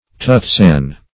Tutsan \Tut"san\, n. [F. toutesaine; tout, toute, all (L. totus)